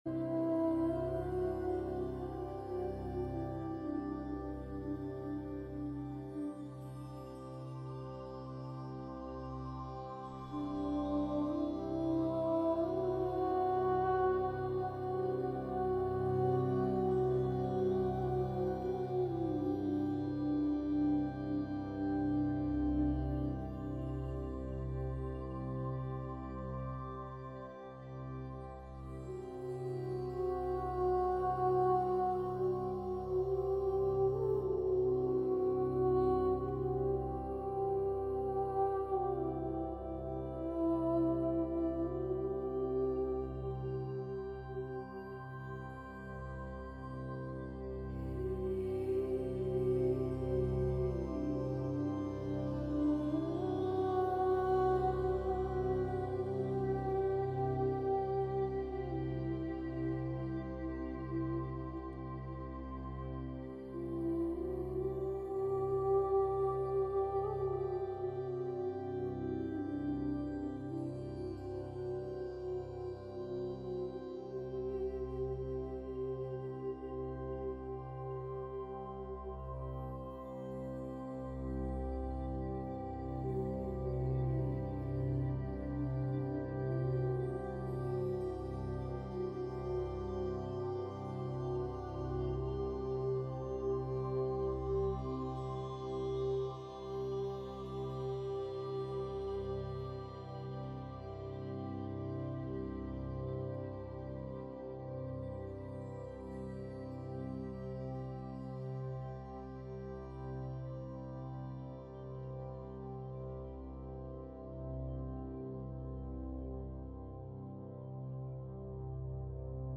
Sound Healing Recording Project—take 1